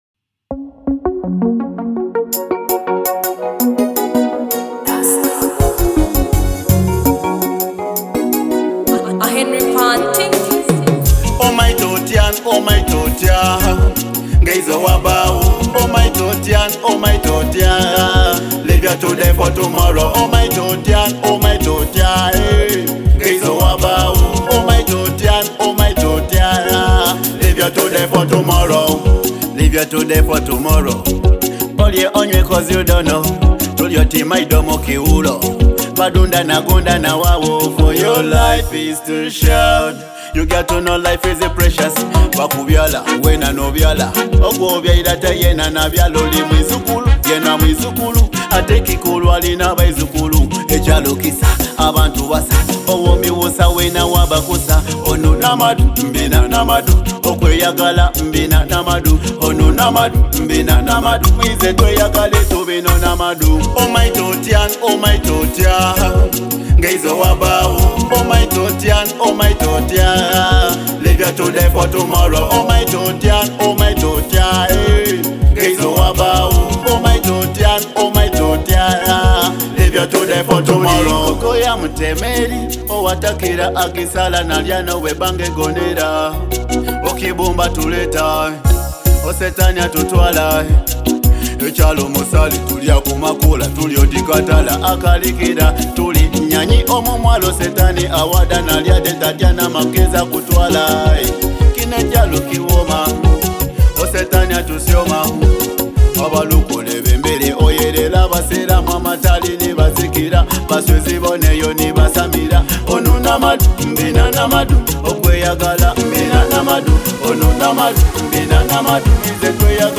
Genre: Afro Pop